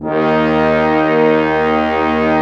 55m-orc06-D#2.wav